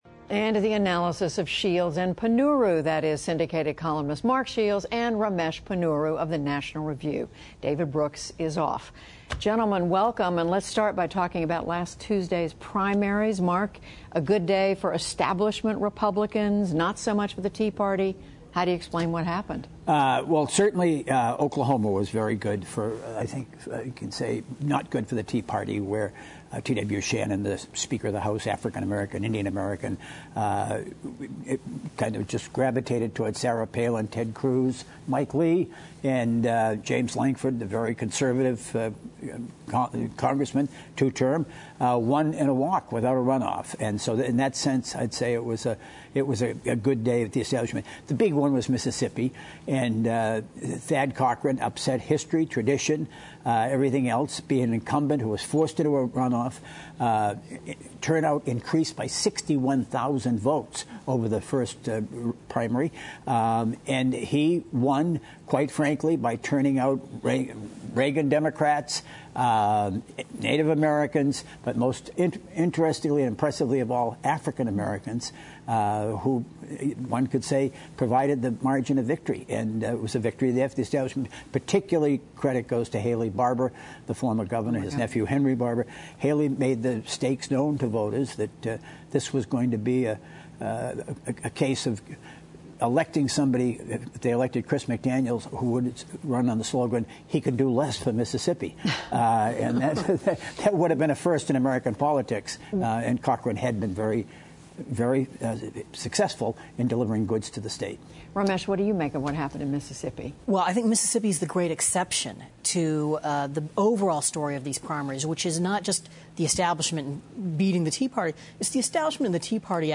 Syndicated columnist Mark Shields and Ramesh Ponnuru of National Review join Judy Woodruff to discuss the week’s top news, including how incumbents held their ground against the tea party in last Tuesday’s primaries, Rep. John Boehner’s threat to sue President Obama for abusing presidential powers, as well as accusations swirling around missing IRS emails.